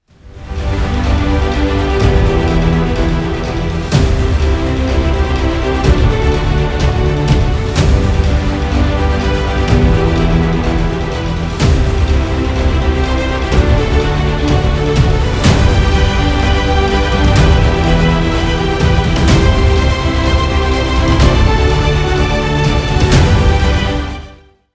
Featured in Instrumental Ringtones